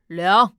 c01_5胖小孩倒数_2.wav